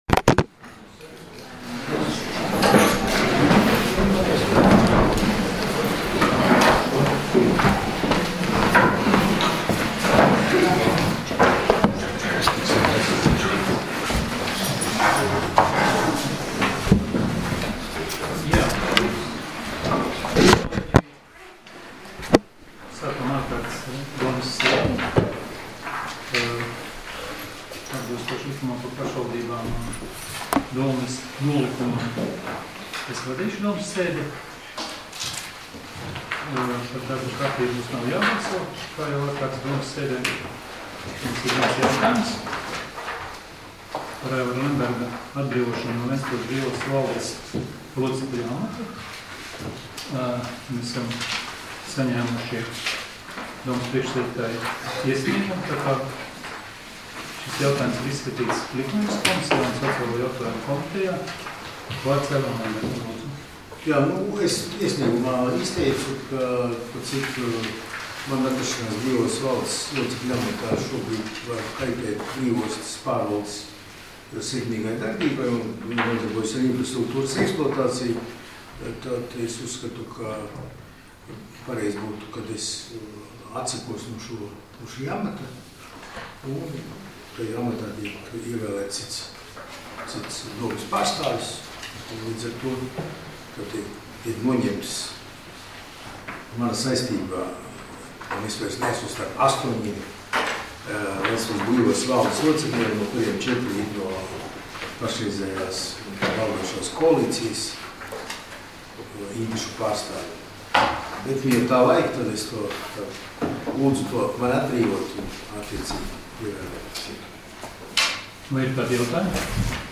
Ārkārtas Domes sēdes 10.12.2019. audioieraksts